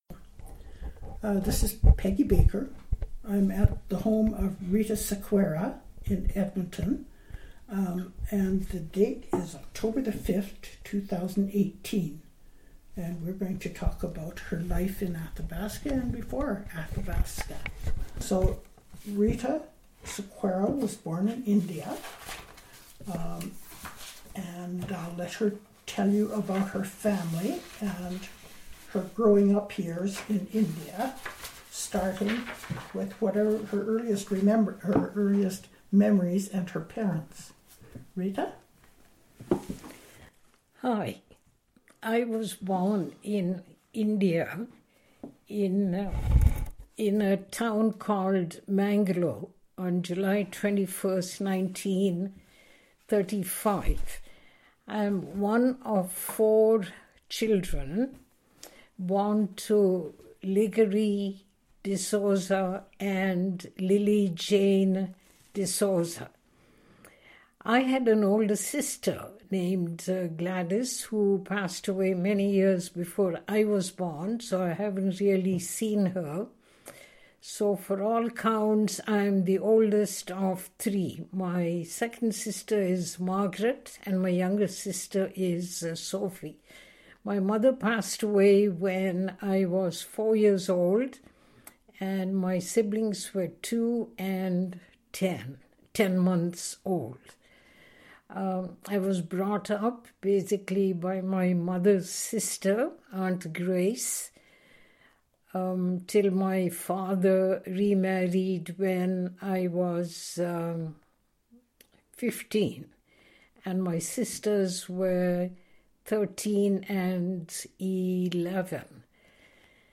Audio interview,